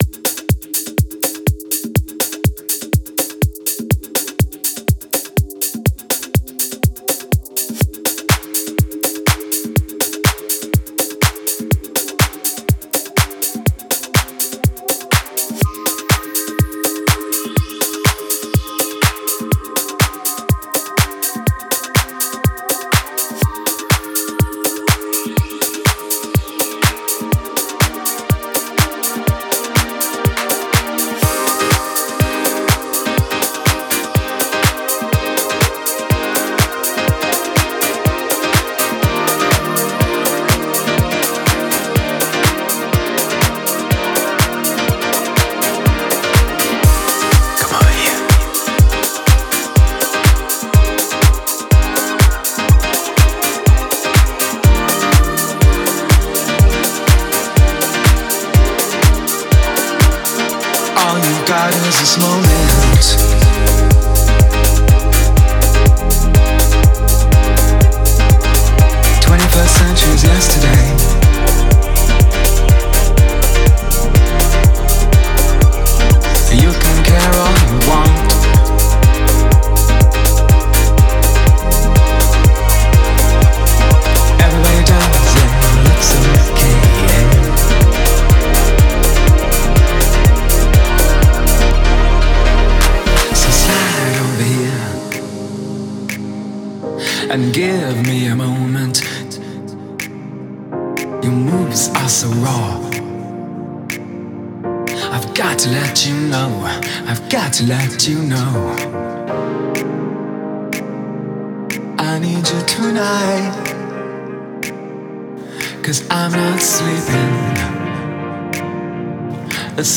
Y para que vayas entrando en ambiente, aquí te dejamos un adelanto musical con la esencia del evento: